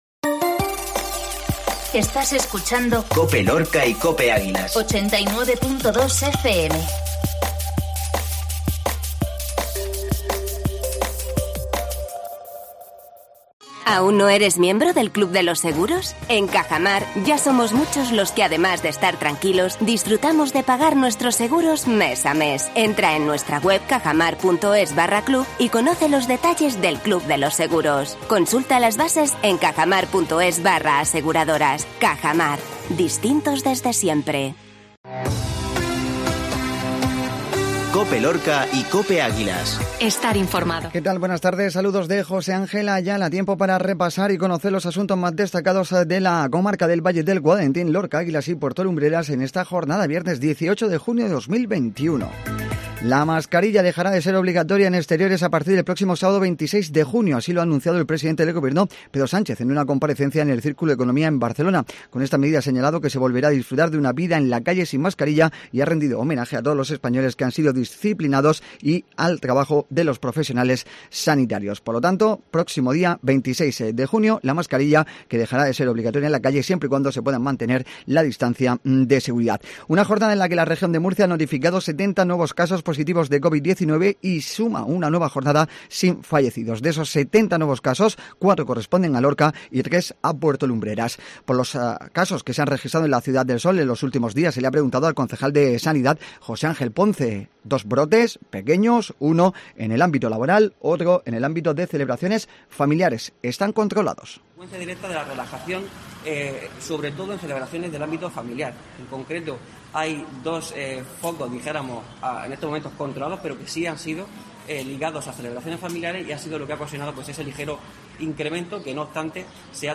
INFORMATIVO MEDIODÍA VIERNES